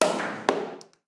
Tapón de una botella de cava
Sonidos: Especiales
Sonidos: Hostelería